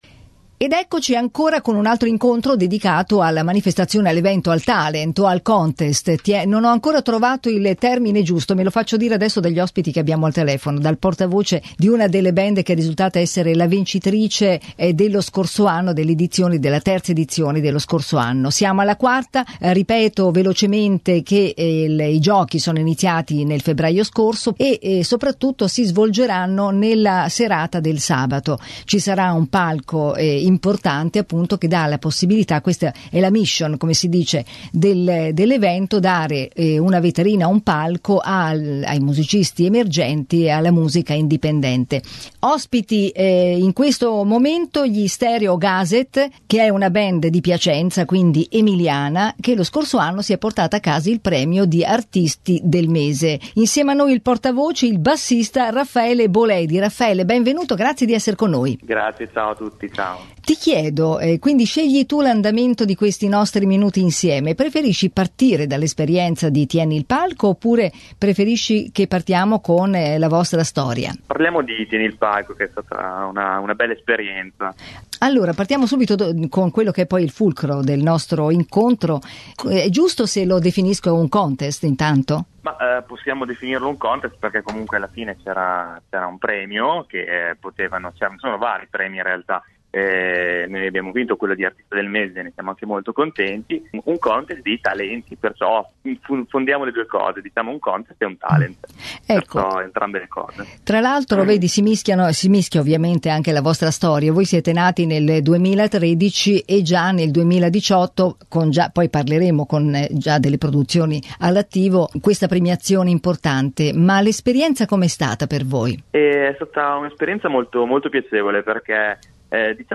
A fare da portavoce per il gruppo nel nostro colloquio